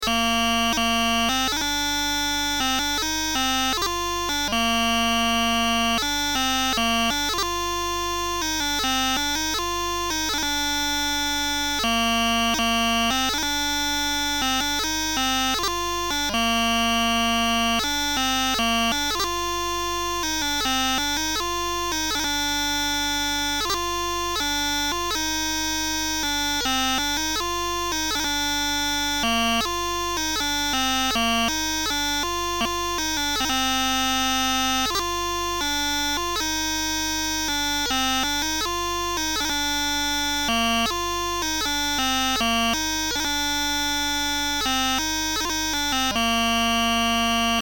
Tours suite